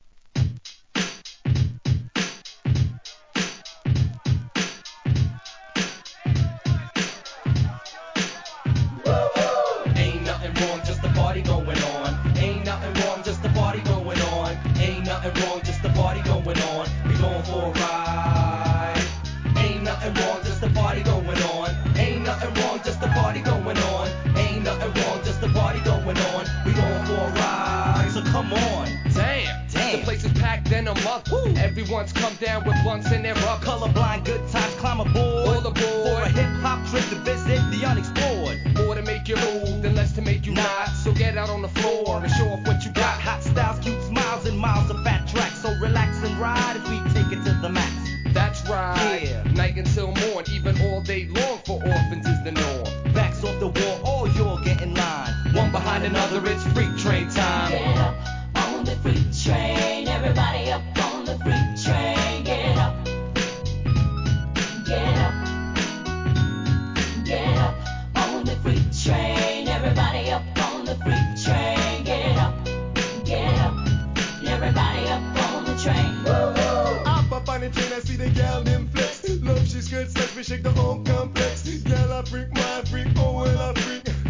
HIP HOP/R&B
キャッチーなネタ使いにフィメール・コーラ、ラガMCが絡む1997年作品!!